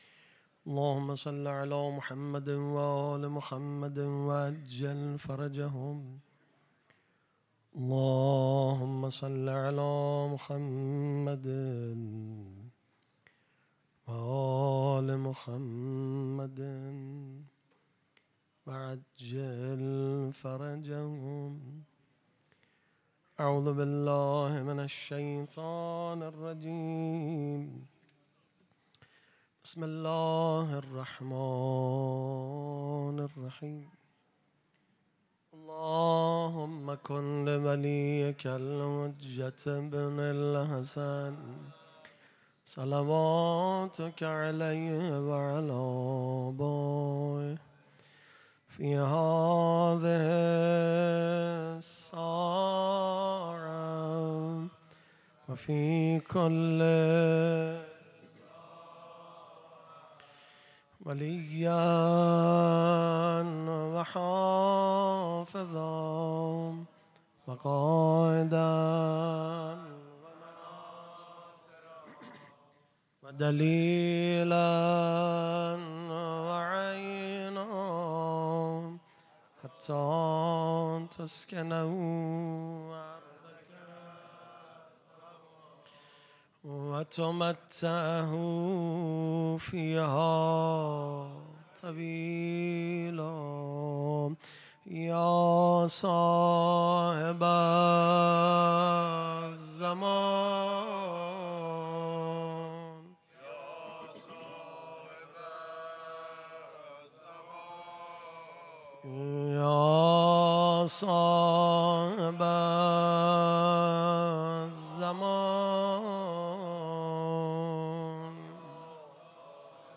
پیش منبر
دانلود تصویر پیش منبر favorite شام وفات حضرت ام‌البنین(س) پیش منبر